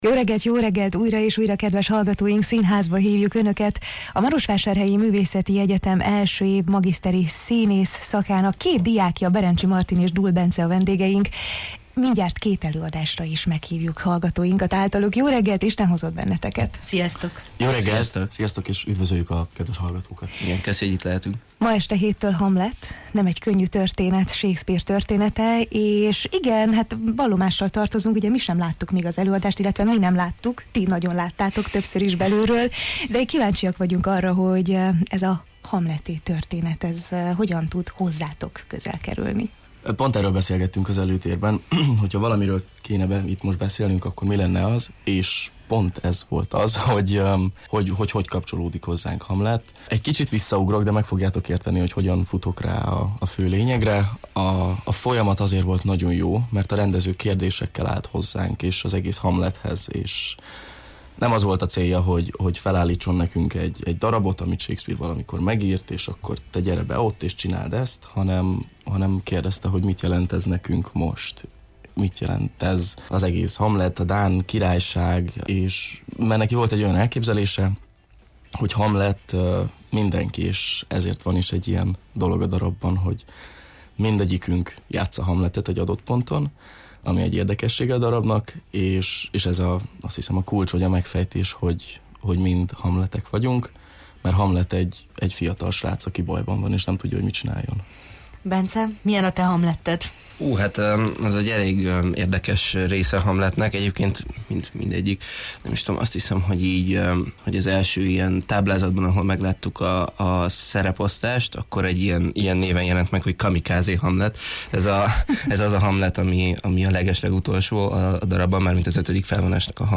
A Marosvásárhelyi Művészeti Egyetem I. éves magiszteri színész szak hallgatói (pajtik) voltak a Jó reggelt, Erdély! vendégei: